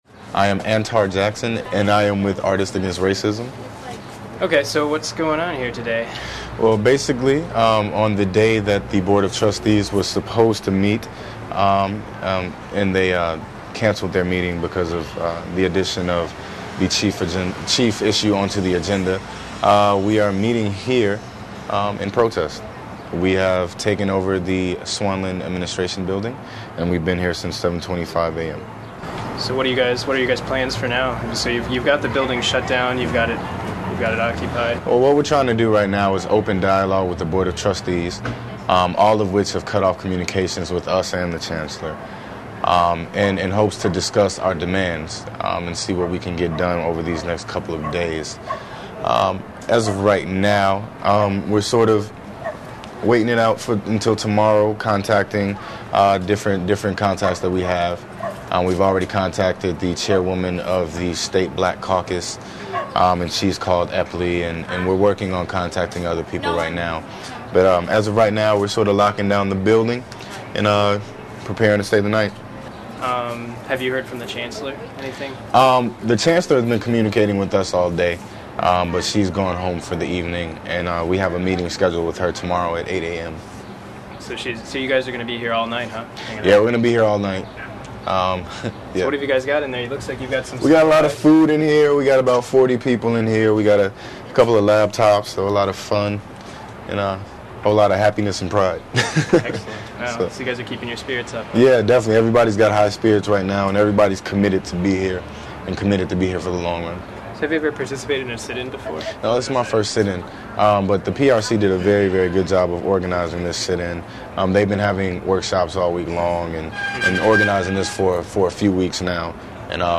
Interview :: Protest Activity